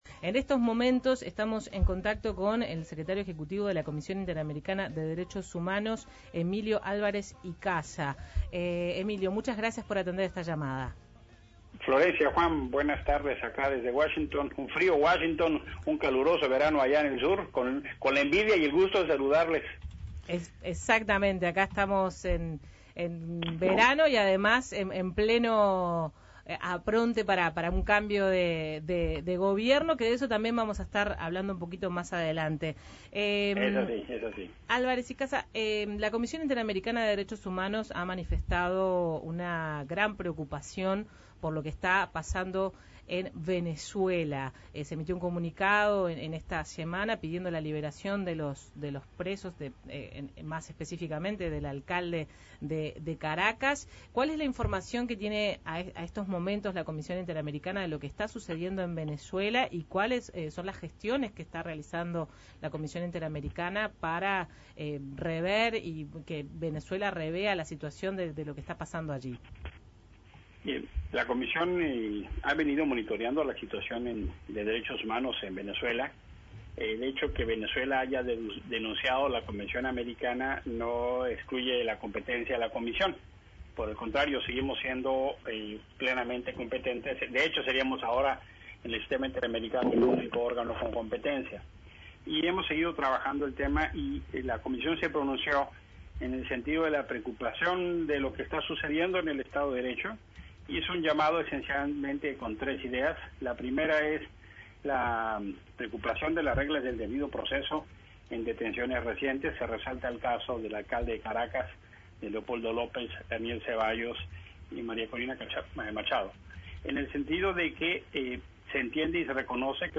El secretario ejecutivo de la Comisión Interamericana de Derechos Humanos (CIDH), Emilio Álvarez Icaza, dijo a El Espectador que la detención de Antonio Ledezma "fue arbitraria" y expresó su preocupación por la falta de diálogo "pacífico" que hay en Venezuela.